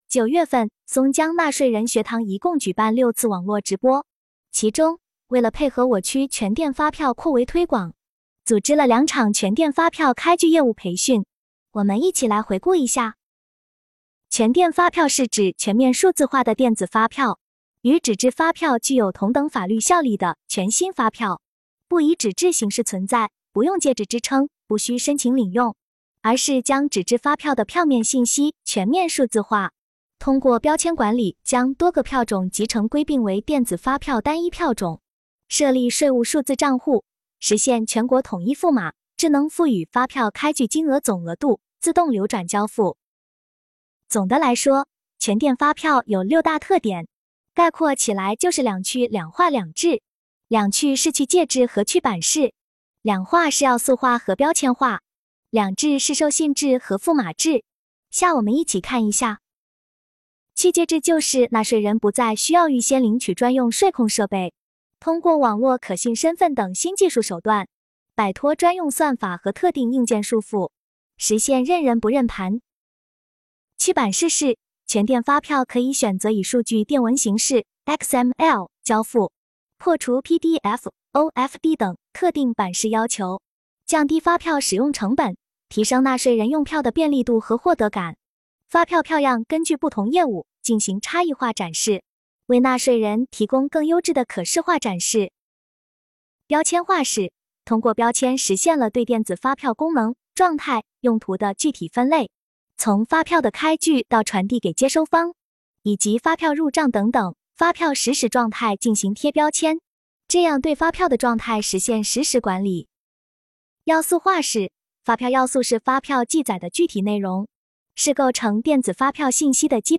目前，松江区税务局通过网络直播的形式开展纳税人学堂。